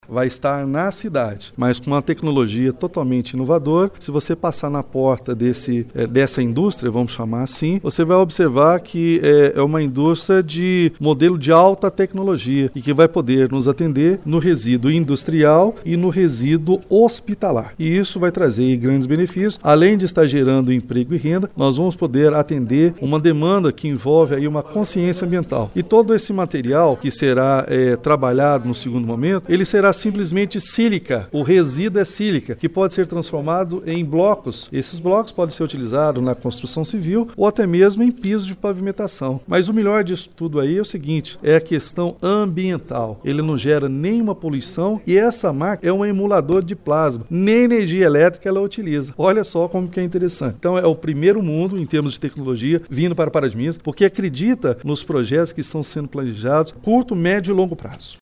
Na noite de quarta-feira, 26 de setembro, a prefeitura de Pará de Minas realizou no plenário da Câmara Municipal a audiência pública para prestação de contas dos atos administrativos praticados no segundo quadrimestre de 2018.
Durante seu pronunciamento, o prefeito Elias Diniz (PSD) anunciou uma grande novidade que deverá ser instalada em Pará de Minas em breve.